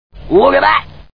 Crocodile Hunter: Collision Course Movie Sound Bites